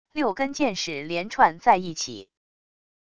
六根箭矢连串在一起wav音频